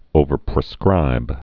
(ōvər-prĭ-skrīb)